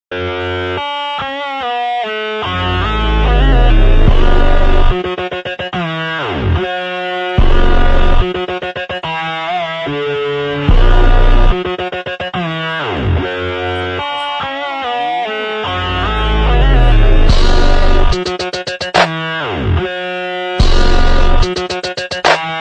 Cat sound effects free download